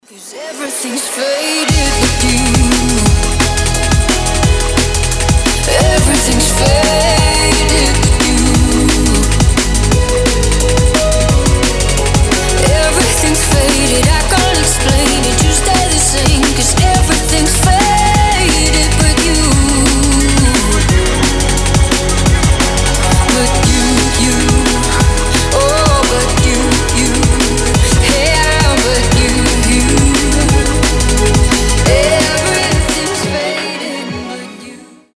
• Качество: 320, Stereo
громкие
женский голос
драм энд бейс